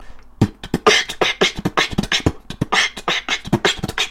Один такт = четыре звука, к примеру b - t b | kch - t kch | - kch t b | kch tbt kch (tbt - занимает место под два звука)